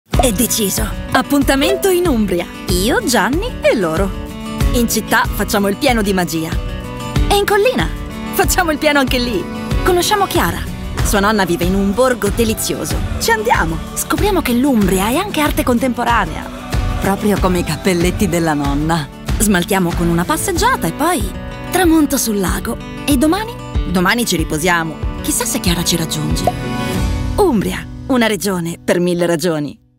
Fresco, frizzante, energico